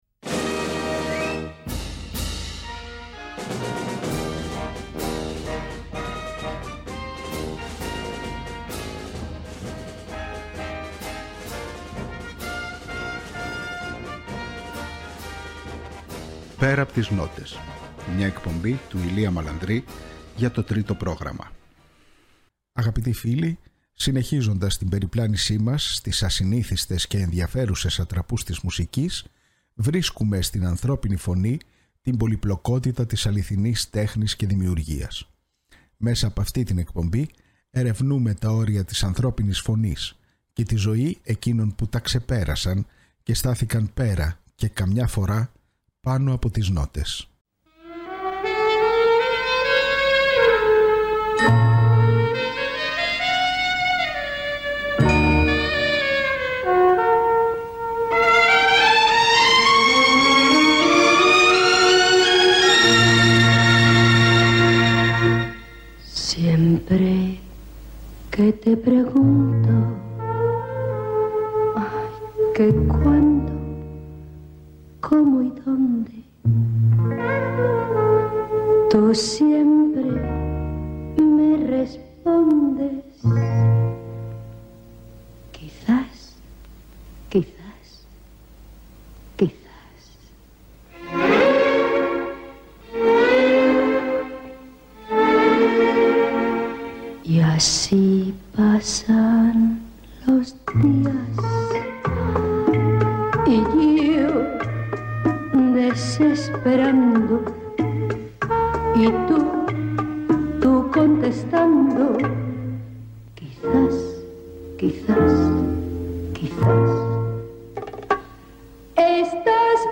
Μέσα από σπάνιο ηχητικό αρχείο συνεντεύξεων και άγνωστων ανέκδοτων ηχογραφήσεων ξετυλίγονται τα Πορτραίτα 30 καλλιτεχνών που άφησαν ένα τόσο ηχηρό στίγμα στην τέχνη καταφέρνοντας να γίνουν σημείο αναφοράς και να εγγραφούν στην ιστορική μνήμη, όχι μόνο ως ερμηνευτές αλλά και ως σύμβολα.